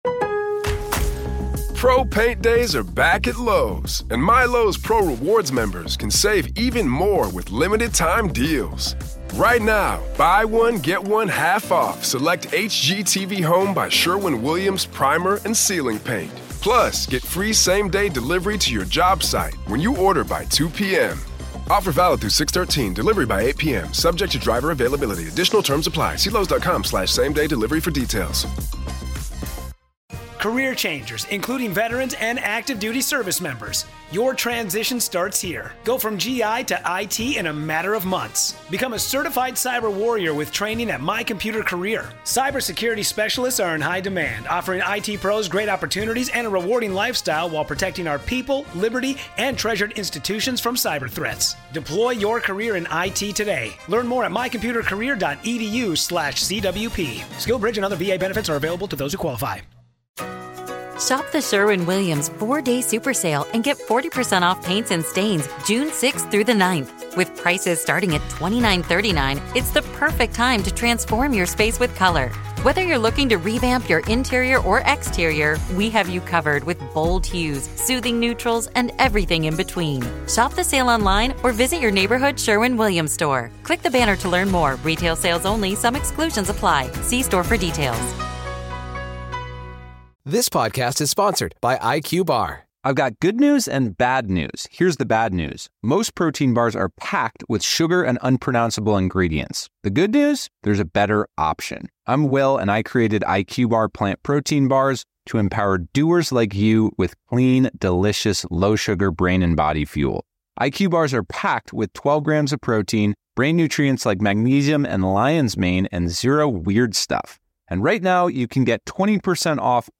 This panel-based episode offers a no-spin, multi-expert perspective that unpacks the legal strategy, forensic data, psychological cues, and the impact of media on the upcoming trial.